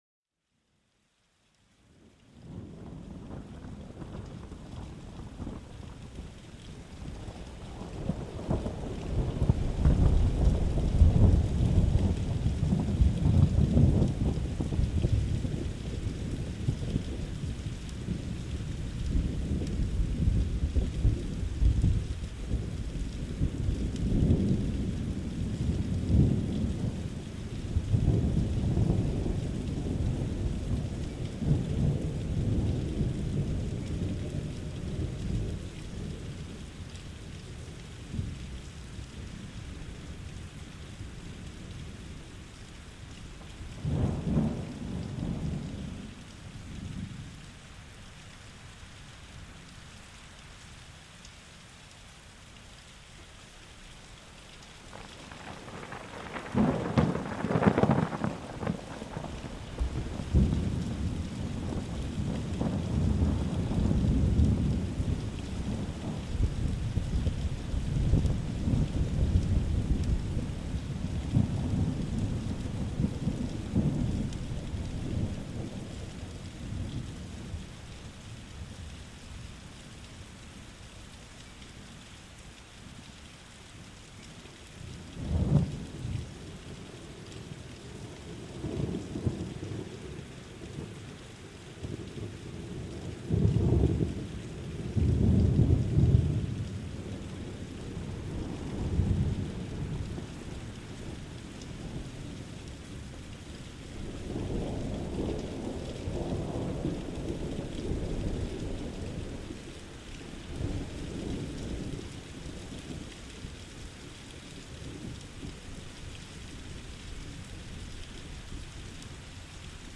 Plongez dans l'intensité d'un gros orage, avec des éclairs qui illuminent le ciel et des roulements de tonnerre puissants. Laissez-vous envelopper par le son de la pluie battante, un véritable spectacle sonore pour vos oreilles.